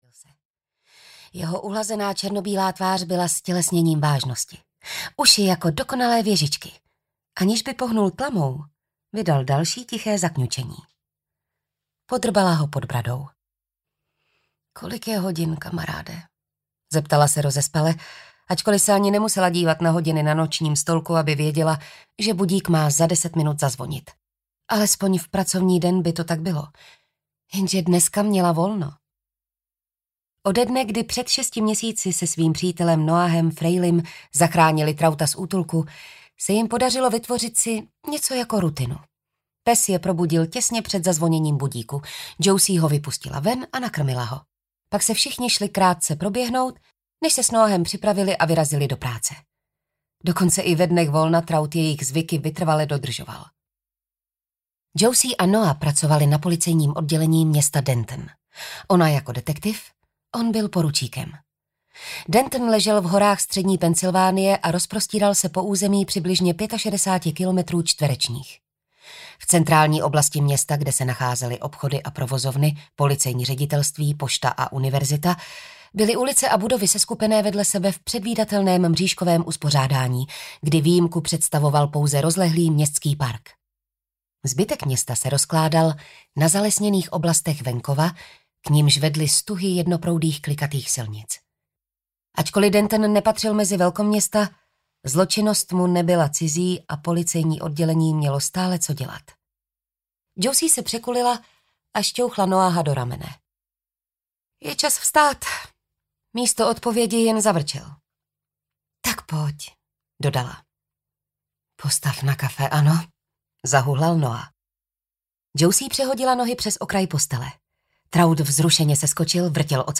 Závod s časem audiokniha
Ukázka z knihy
zavod-s-casem-audiokniha